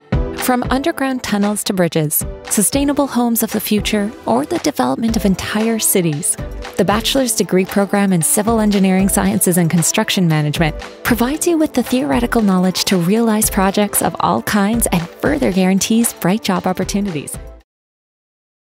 Explainer Videos
Rode NT 1, SSL2 Interface, Reaper, Custom Built Studio PC, Sound Booth, Live Direction
Mezzo-SopranoSoprano